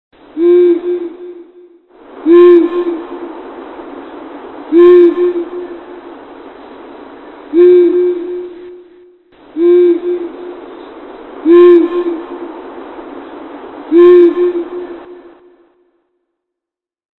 Waldohreule
Das Weibchen antwortet in ähnlich monotoner Weise. Daneben besitzen sie ein großes Repertoire an Alarmrufen. zurück zur Übersicht >
waldohreule.mp3